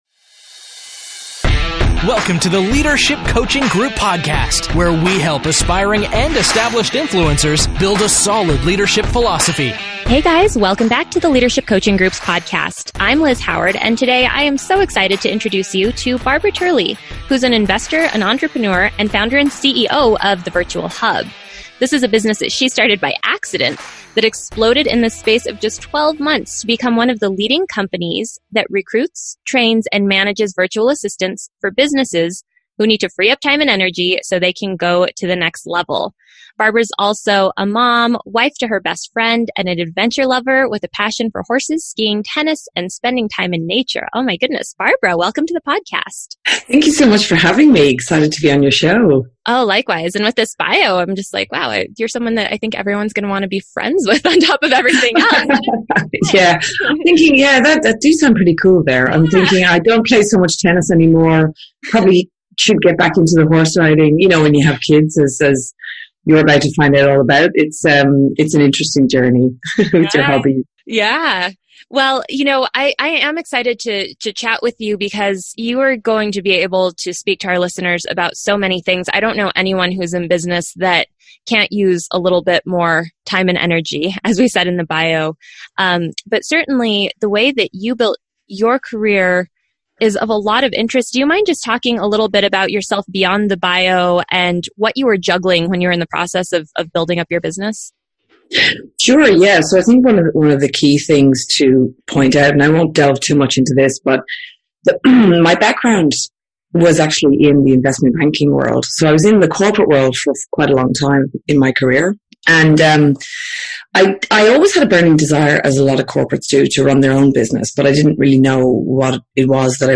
Episode breakdown Today we are talking to a very successful woman who seems to be superwoman.